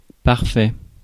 Ääntäminen
France: IPA: [paʁ.fɛ]